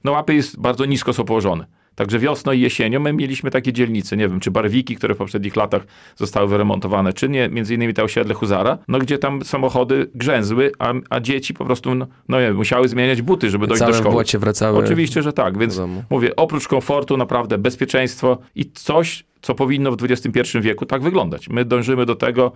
Jak mówi burmistrz Łap Krzysztof Gołaszewski, te inwestycje znacząco poprawią komfort życia mieszkańców.